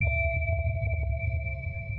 sonarPingSuitMedium1.ogg